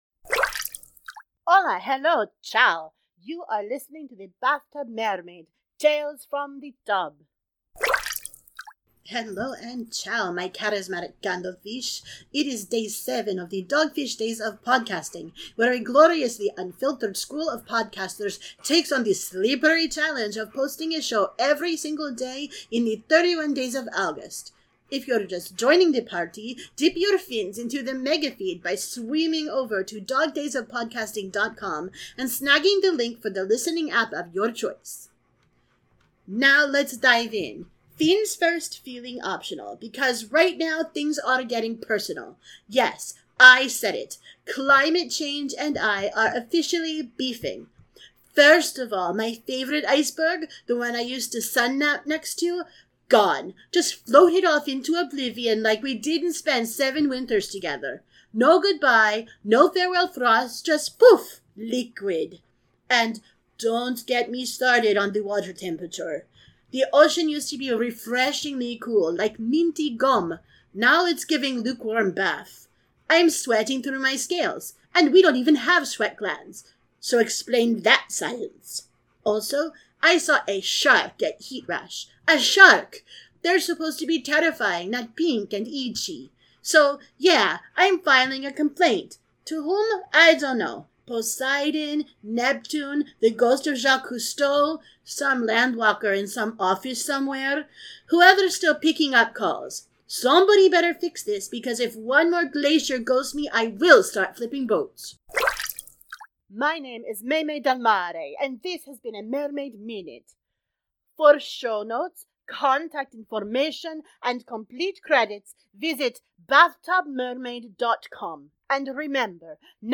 • Sound Effects are from Freesound.